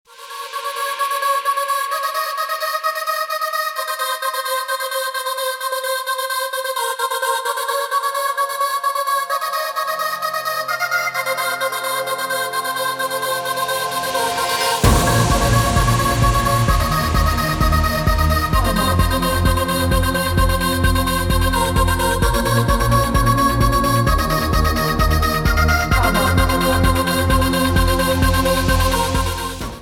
• Качество: Хорошее
• Категория: Рингтоны